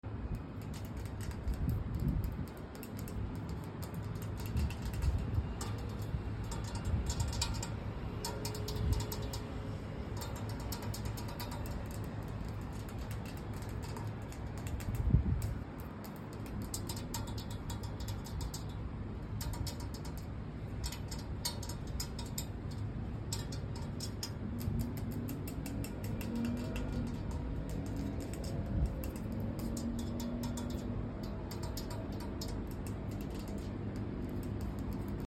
Outdoor asmr on my balcony sound effects free download
Outdoor asmr on my balcony in Hawaii